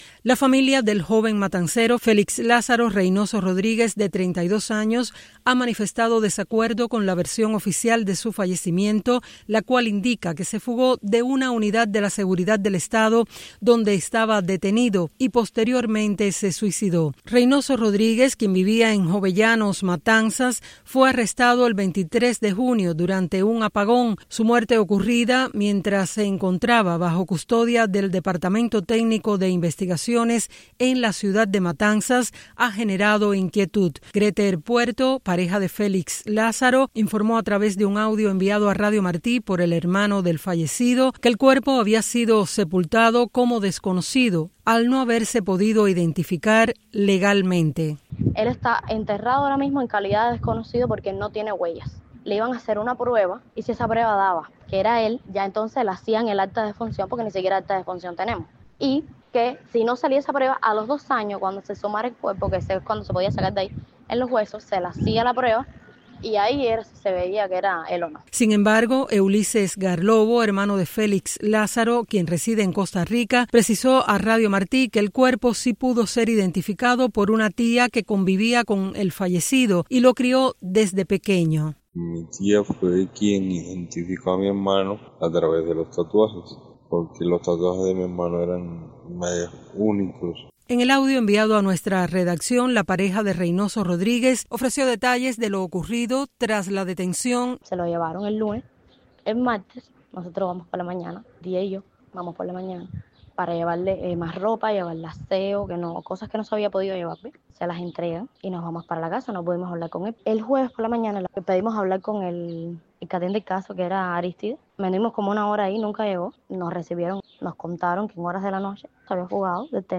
Las principales informaciones relacionadas con Cuba, América Latina, Estados Unidos y el resto del mundo de los noticieros de Radio Martí en la voz de nuestros reporteros y corresponsales